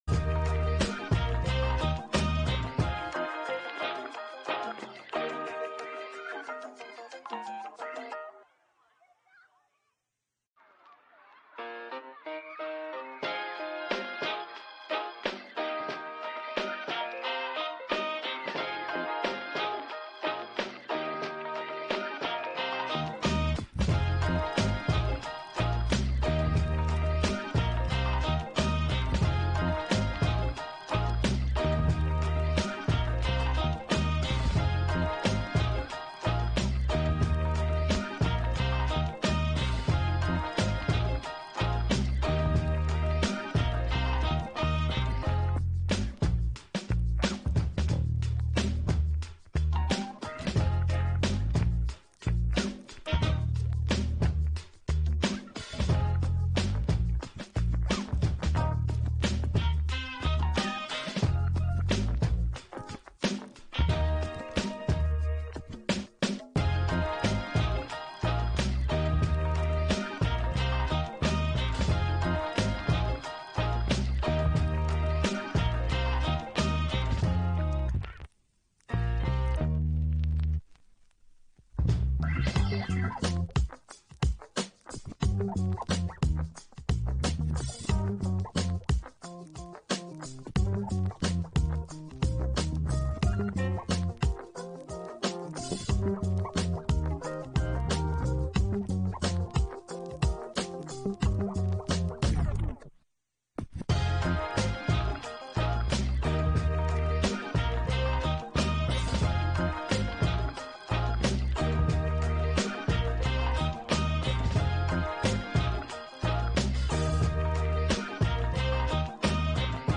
Live post match analysis of BSC Young Boys vs Manchester United!